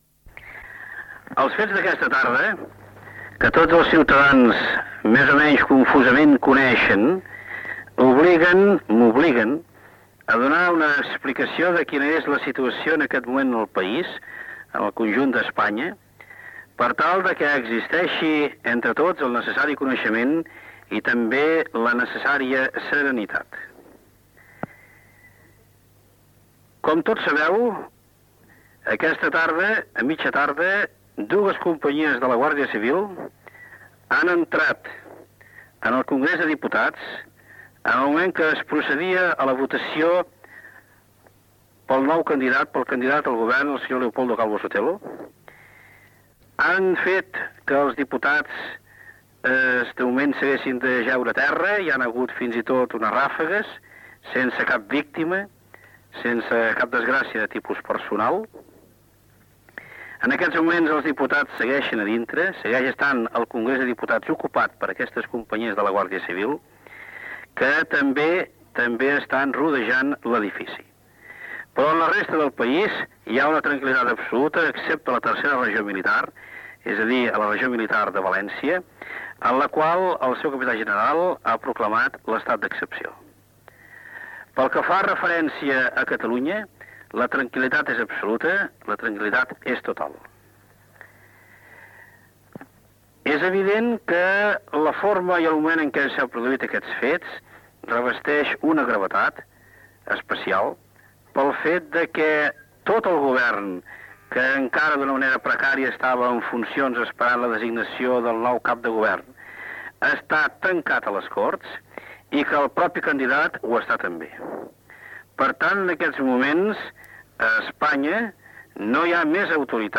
Missatge del president de la Generalitat, Jordi Pujol, per informar i opinar sobre l'entrada de la Guardia Civil al Congrés dels Diputats de Madrid per fer un cop d'estat i la situació militar a València i manifestar que, al vespre, havia parlat amb el Rei Juan Carlos I i el capità general de Catalunya.
Informatiu
FM